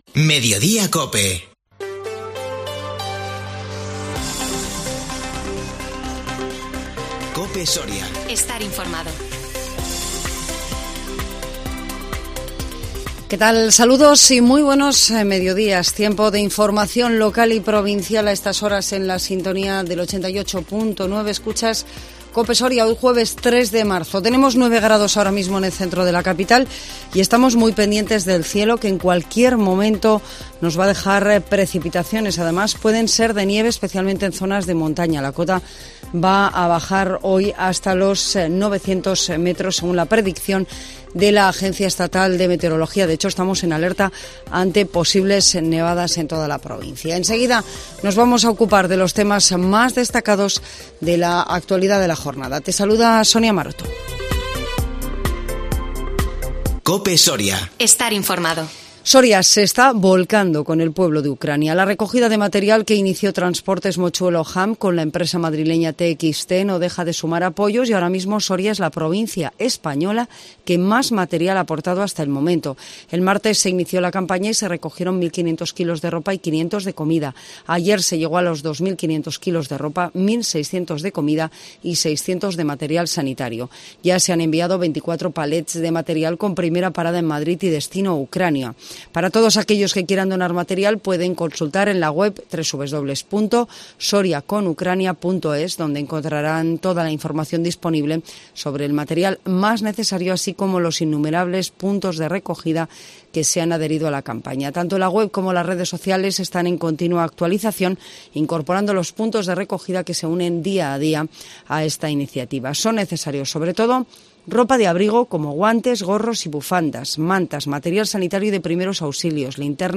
INFORMATIVO MEDIODÍA COPE SORIA 3 MARZO 2022